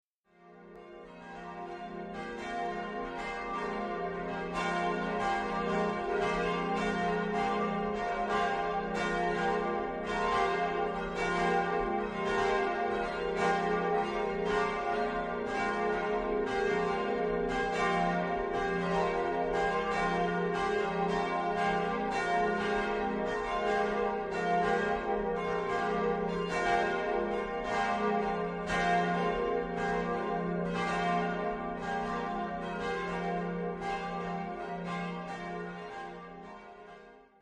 Glocken
Im Kirchturm hängen vier Glocken mit unterschiedlichem Gewicht und Alter.
1980 wurde von der Glockengießerei Schilling (Apolda) eine kleine Glocke hinzugegossen (b‘, 250 kg, 75 cm).
Die-Glocken-der-Marienkirche-Goldbach.mp3